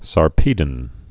(sär-pēdn, -pēdŏn)